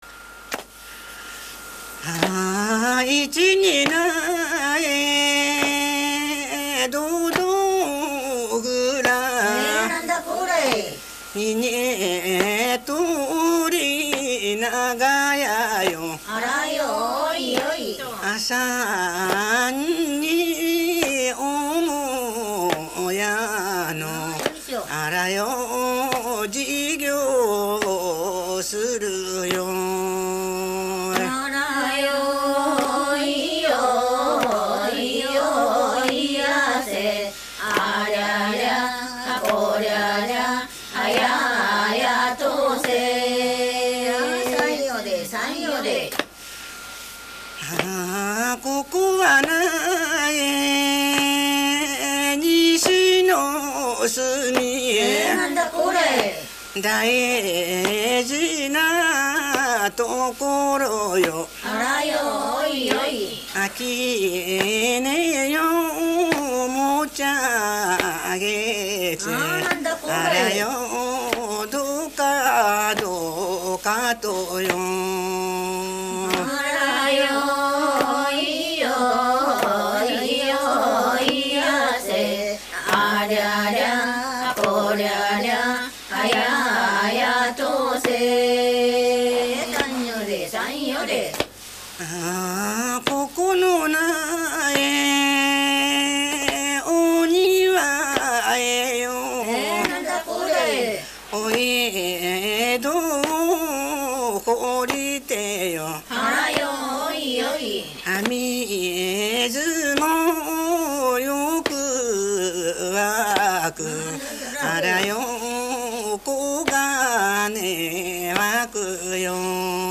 胴突歌 労作歌